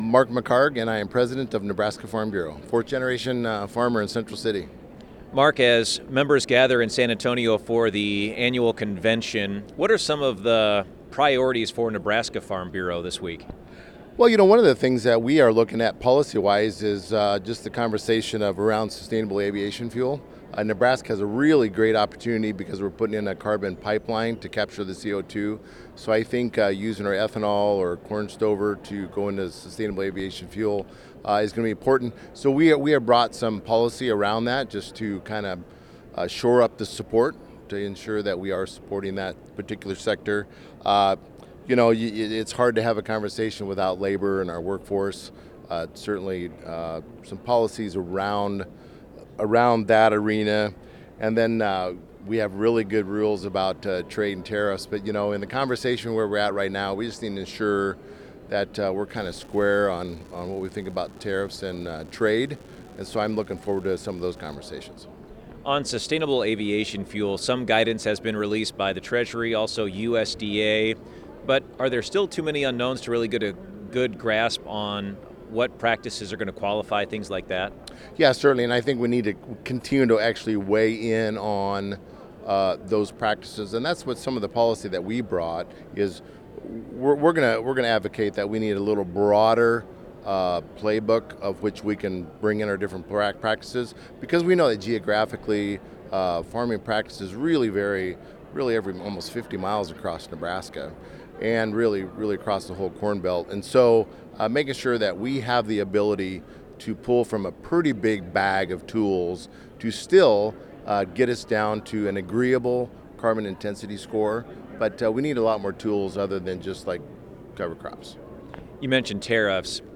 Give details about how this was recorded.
Speaking to Brownfield at the American Farm Bureau Convention in San Antonio, Texas, he said Nebraska is developing a pipeline to capture carbon dioxide.